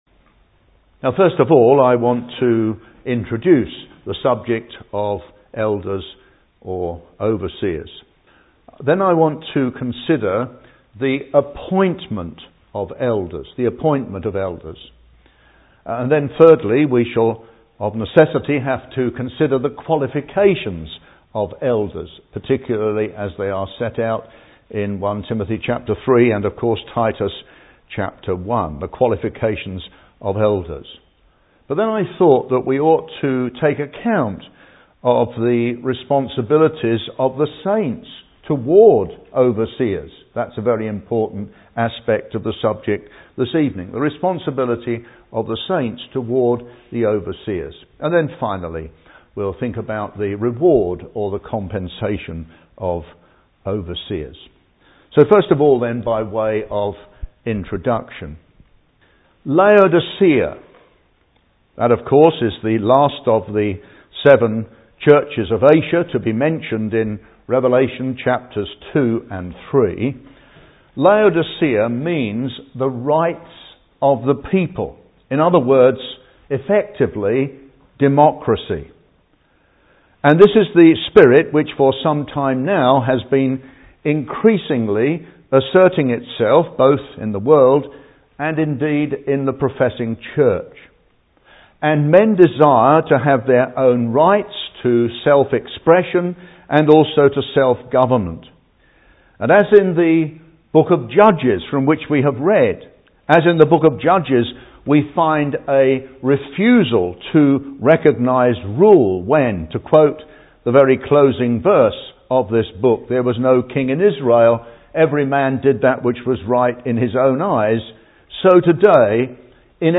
After introducing the Biblical concept of "rule", he speaks on the appointment of elders, their qualifications and rewards, majoring on Titus Ch 1. He also touches on the responsibility of the flock towards its shepherds (Message preached 3rd May 2012)